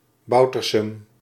Boutersem (Dutch pronunciation: [ˈbʌutərsɛm]
Nl-Boutersem.ogg.mp3